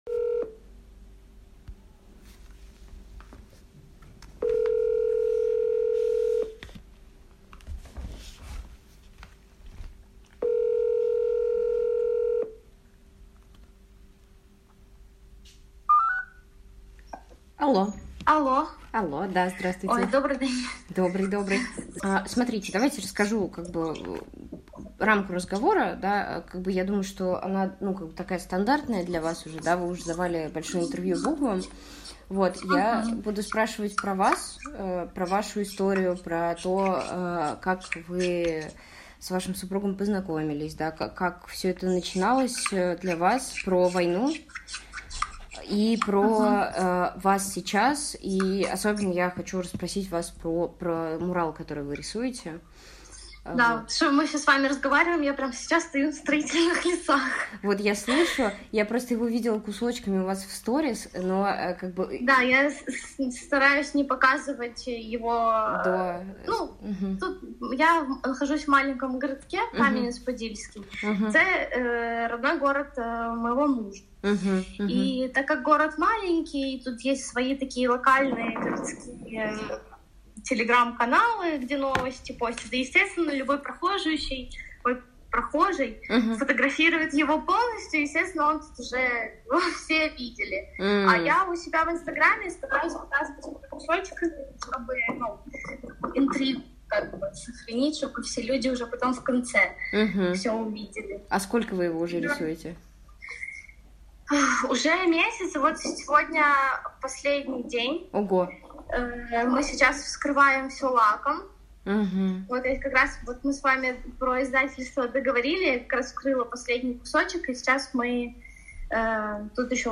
Ты как спартанская жена. Если я погибну, будет больно, но ты переживешь — личные свидетельства войны в Украине, архив «Службы поддержки»